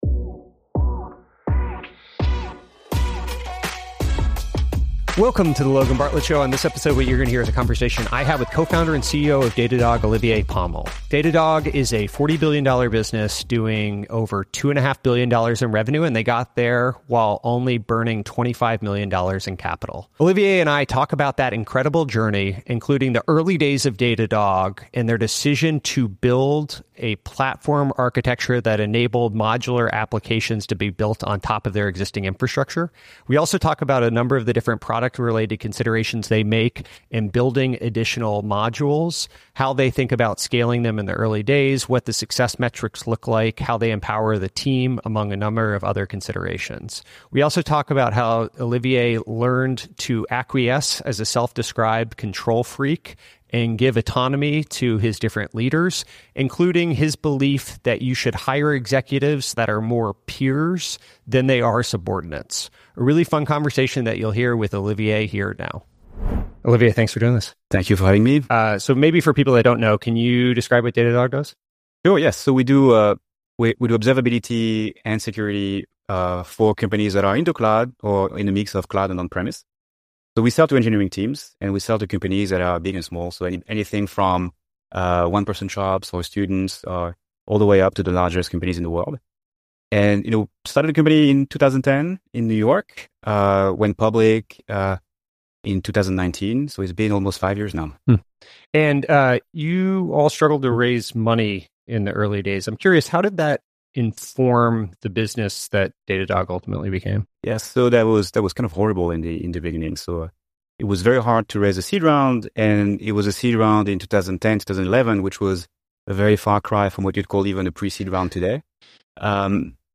EP 108: Olivier Pomel (CEO, Datadog) Shares Every Lesson From Scaling to $40B
Olivier Pomel built Datadog into a $40B company while burning only $25M in capital. In our conversation, he shares the fundraising lessons, operating principles…